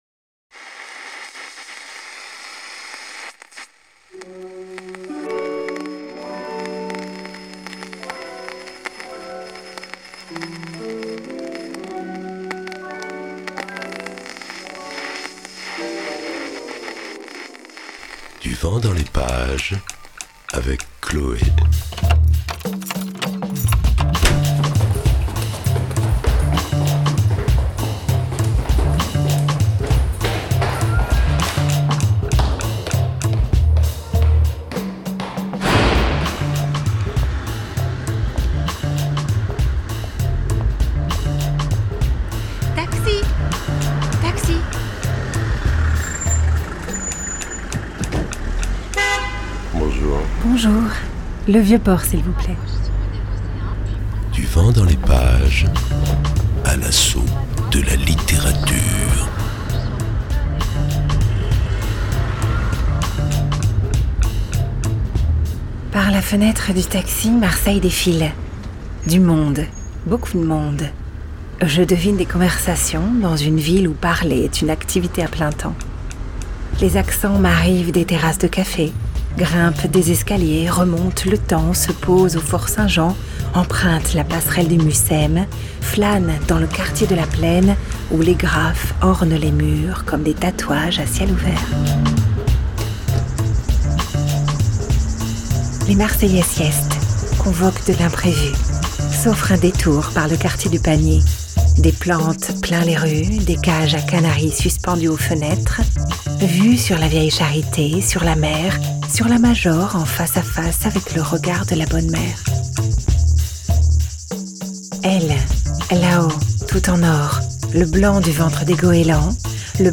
-DVDLP O.BOURDEAUT EP1.mp3 (63.3 Mo) Du Vent dans les Pages vous entraine à la rencontre de l’écrivain OLIVIER BOURDEAUT L’auteur du roman à succès «En attendant Bojangles» publie son troisième roman « FLORIDA » et accepte la rencontre et la conversation. Une rencontre en deux parties, deux émissions en diffusion sur Fréquence Mistral au mois de Juin et de Juillet.